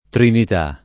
trinit#+] s. f. — sempre con T- maiusc. la T. per antonomasia, la santissima (o Santissima) T.Santa Trinita [